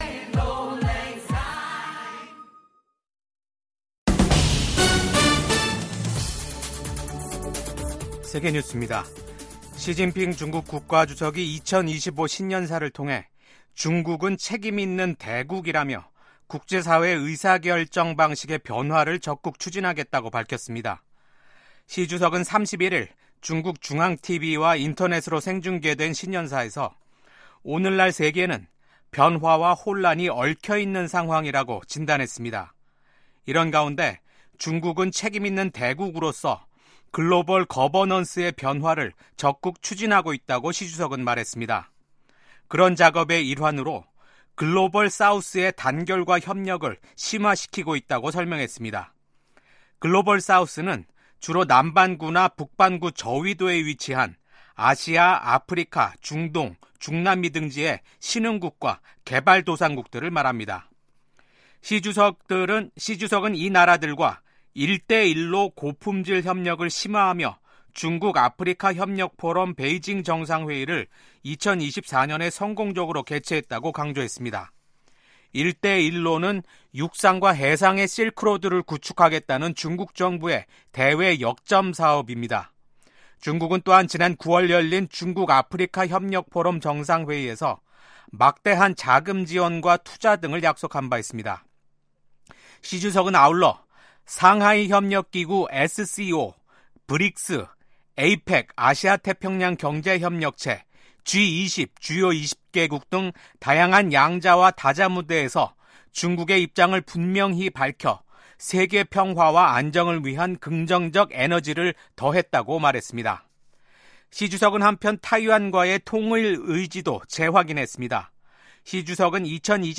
VOA 한국어 아침 뉴스 프로그램 '워싱턴 뉴스 광장'입니다. 미국 국방부는 러시아에 파병된 북한군이 쿠르스크에서 벌이는 공격이 별로 효과가 없다고 평가했습니다.